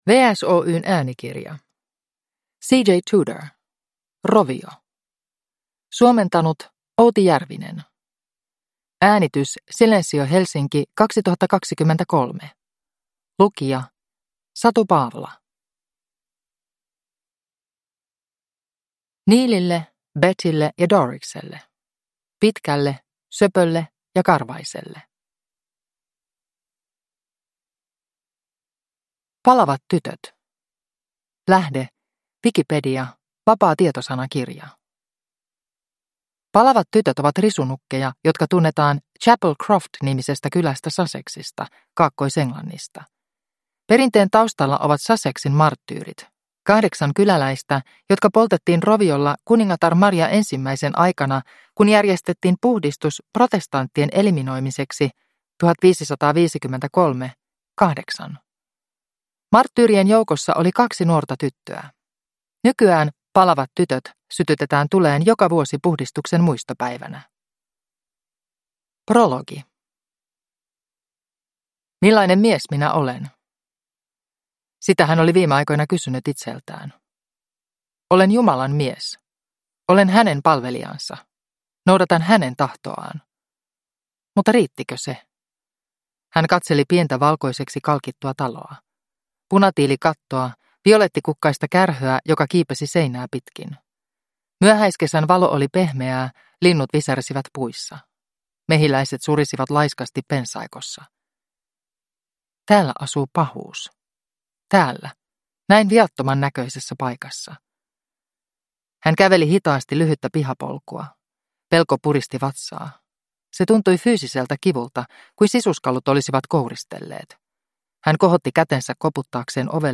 Rovio – Ljudbok – Laddas ner